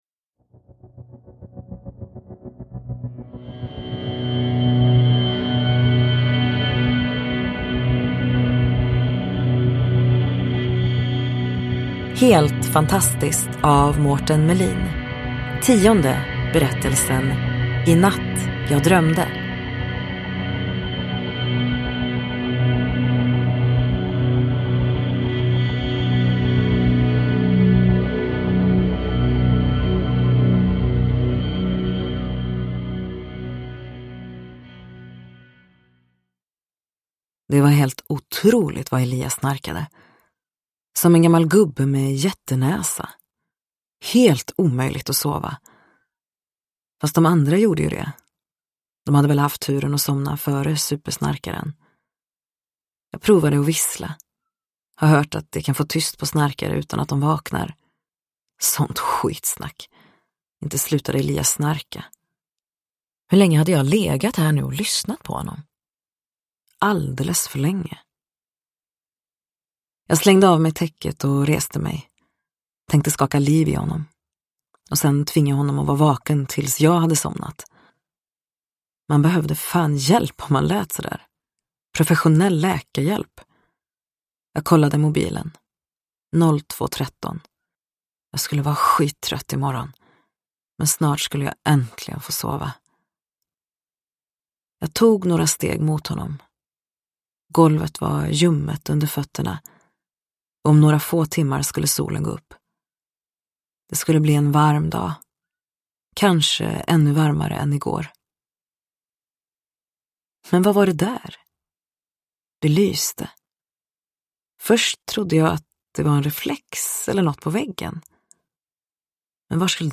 Inatt jag drömde : en novell ur samlingen Helt fantastiskt – Ljudbok – Laddas ner